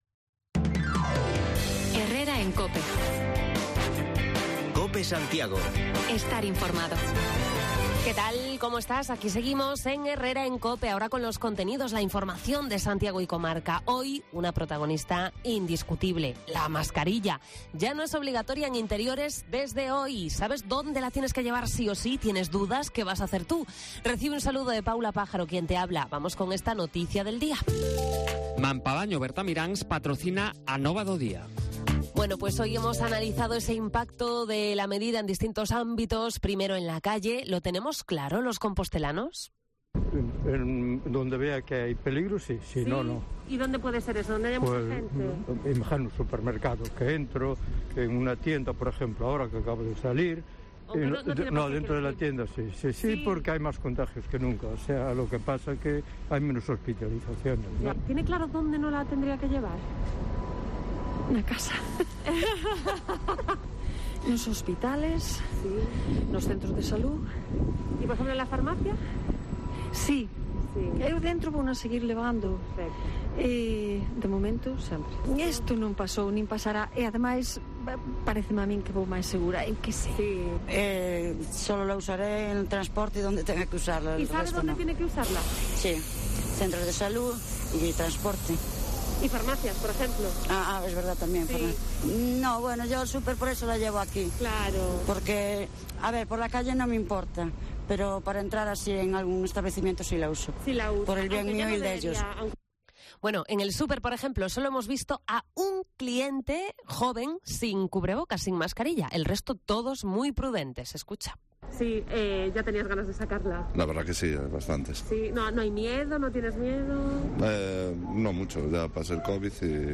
20 de abril: la noticia también en Santiago es la retirada de las mascarillas en la mayoría de los espacios interiores. Recogemos testimonios en distintos lugares, entre otros, el Multiusos Fontes do Sar.